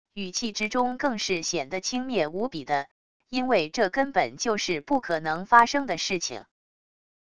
语气之中更是显得轻蔑无比的……因为这根本就是不可能发生的事情wav音频生成系统WAV Audio Player